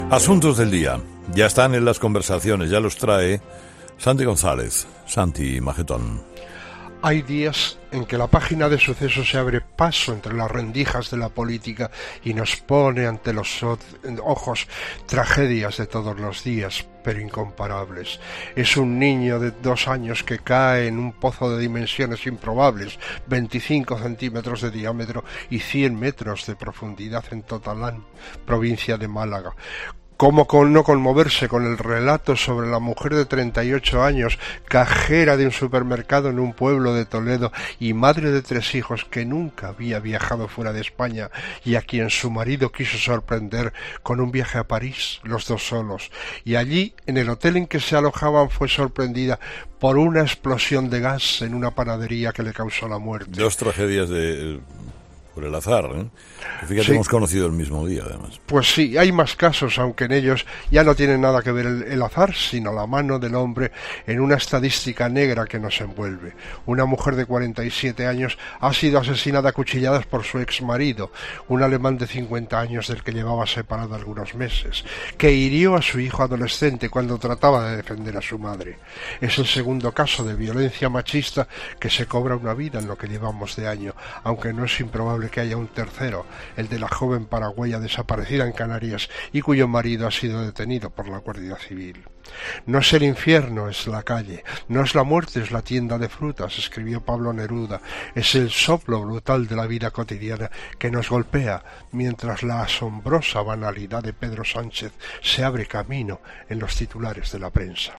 El análisis de la actualidad de Santi González en 'Herrera en COPE'.